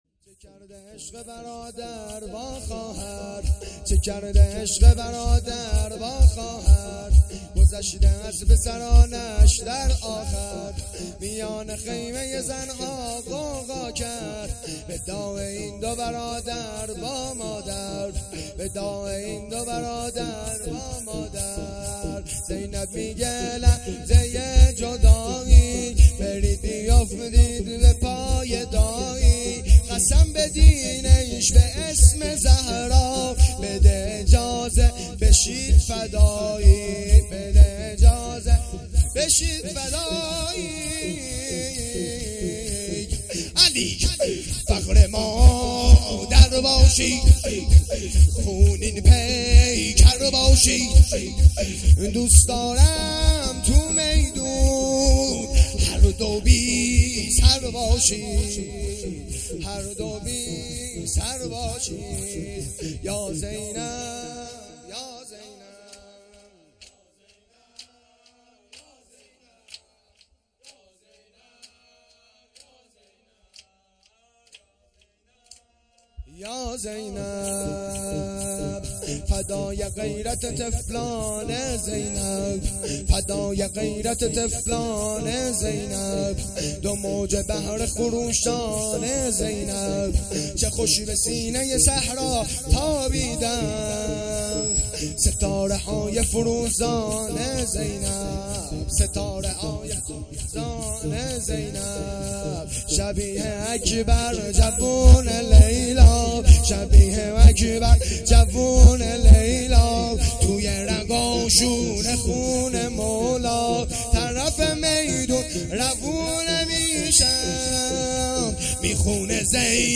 شور
محرم الحرام ۱۴۴۳